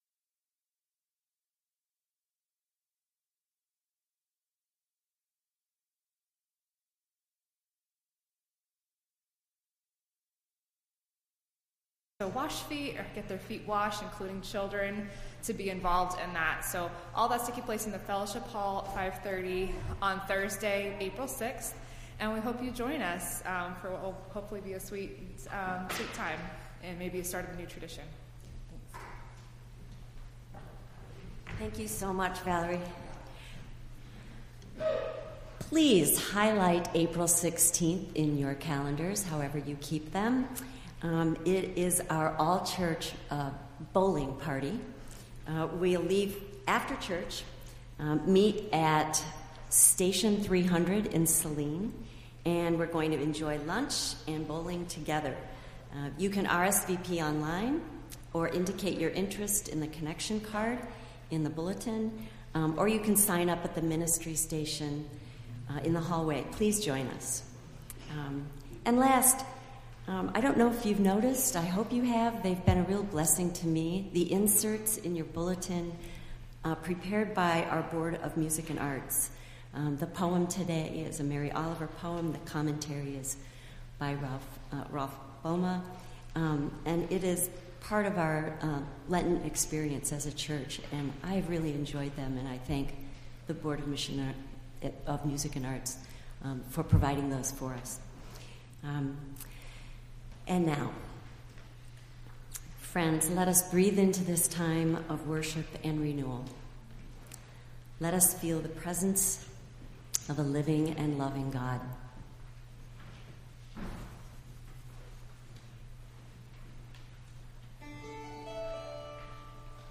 Entire March 26th Service